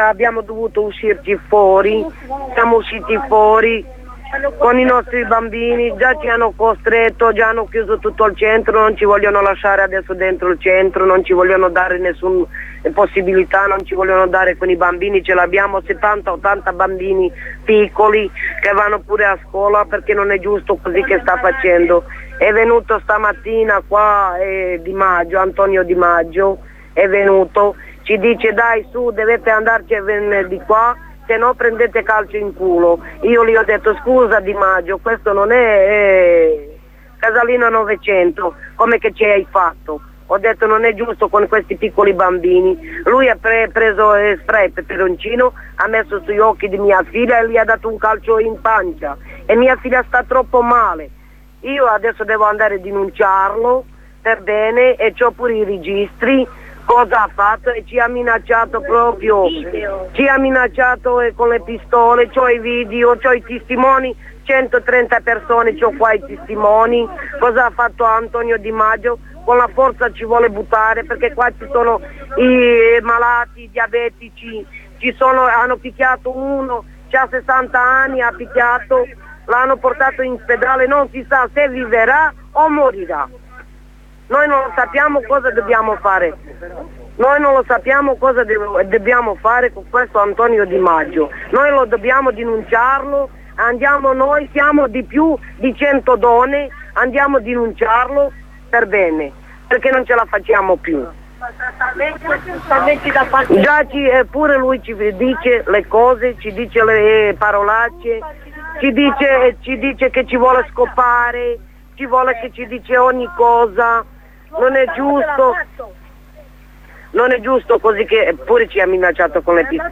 Le testimonianze di alcune delle ospiti del centro di via Amarilli sul comportamento della polizia municipale